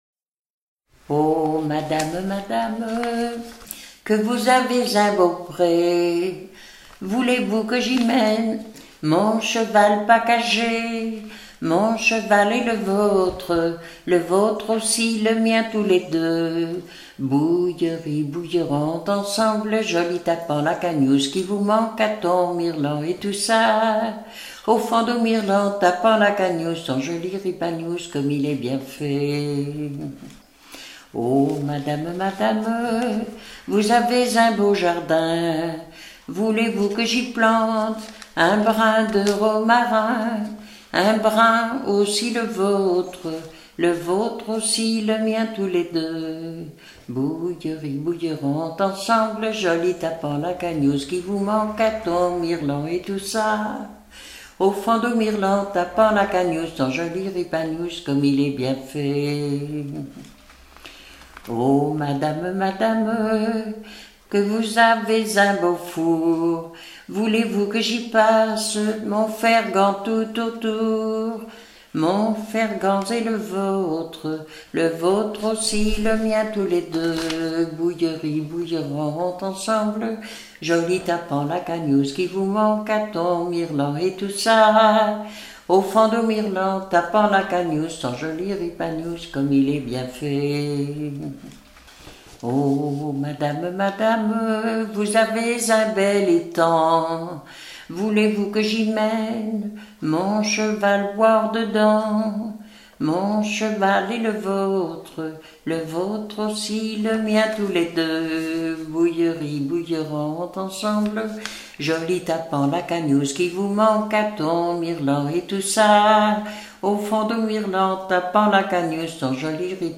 Genre énumérative